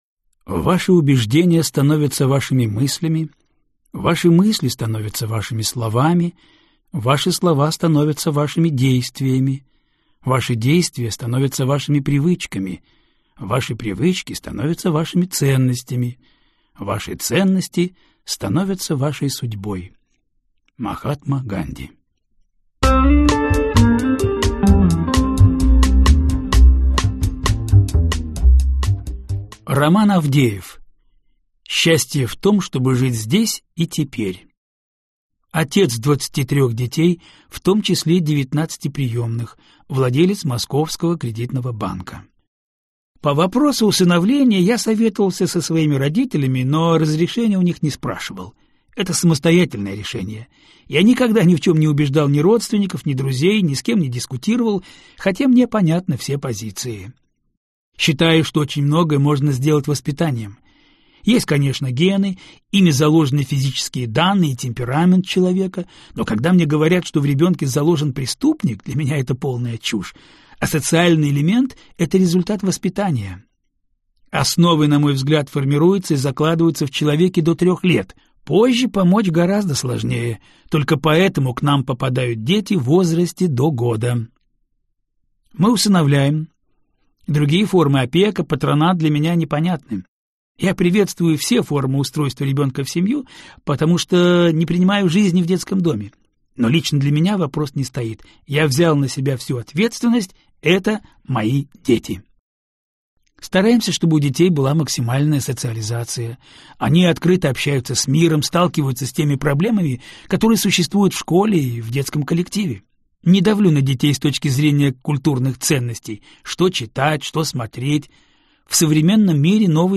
Аудиокнига Главные правила жизни | Библиотека аудиокниг